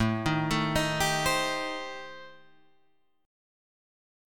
Am7b5 Chord